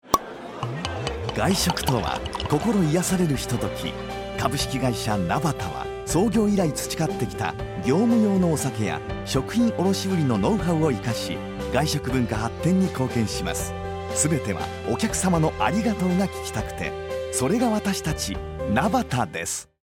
Nabata_Radio_cm20.mp3